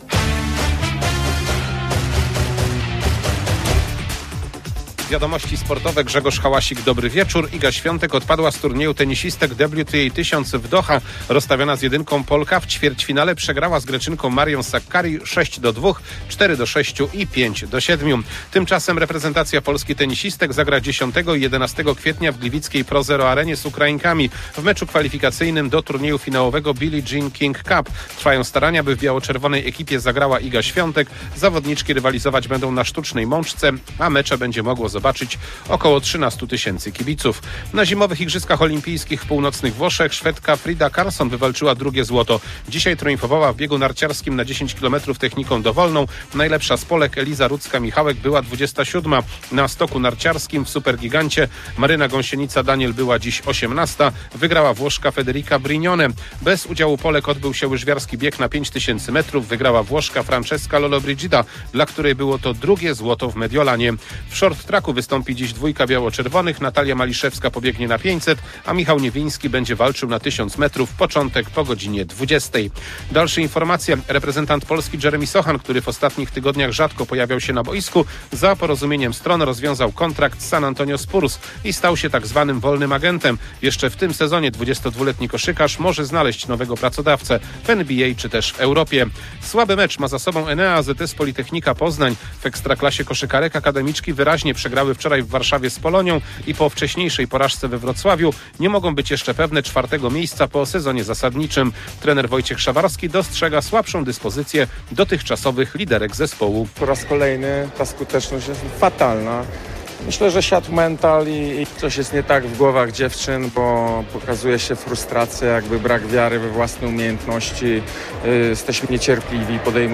12.02.2026 SERWIS SPORTOWY GODZ. 19:05